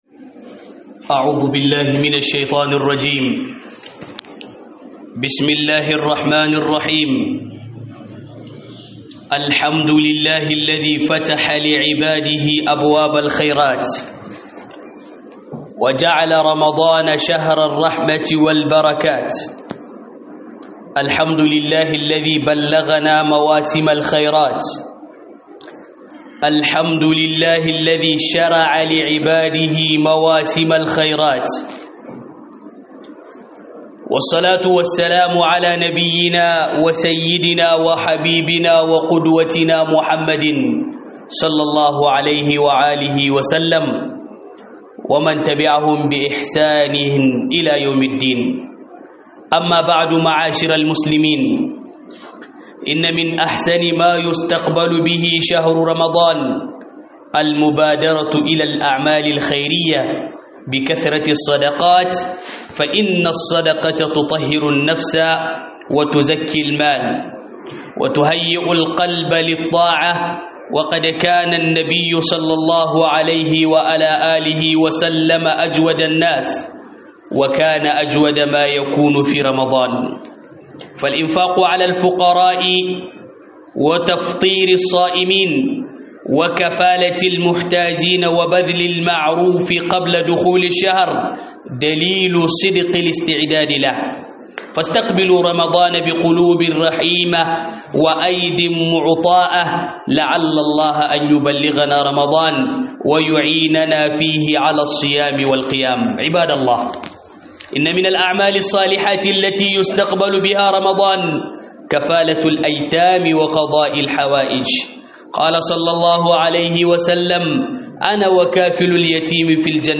Huɗubar juma'a tarbon Ramadana
HUDUBA